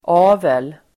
Uttal: ['a:vel]